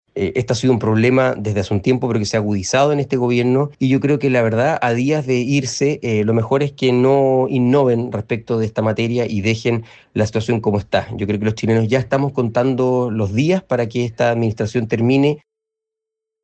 Asímismo, su par y compañero de partido, Stephan Schubert, agregó que a esta altura es mejor que el gobierno actual no innove y deje esta materia tal cual esta.